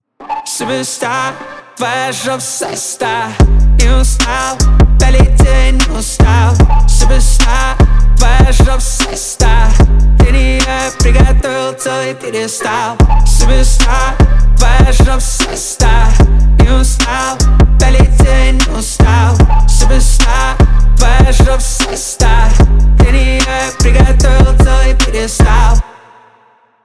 Поп Музыка
клубные # громкие